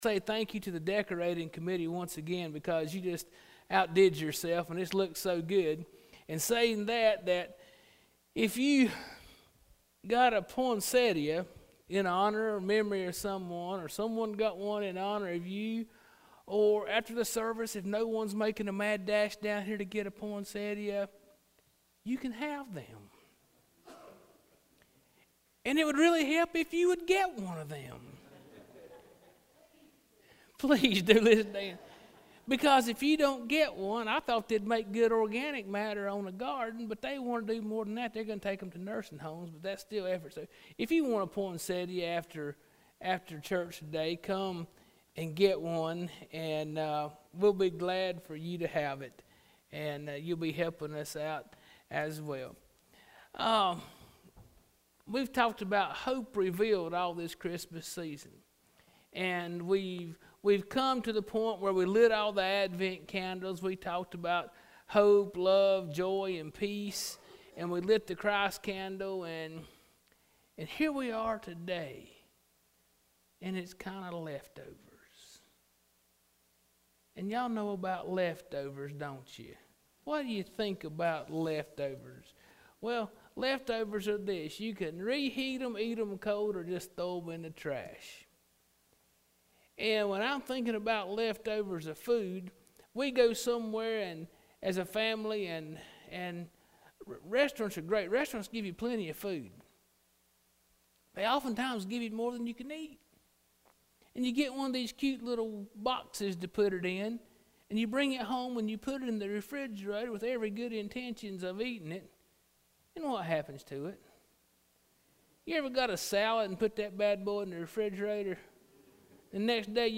Spencer Baptist Church Sermons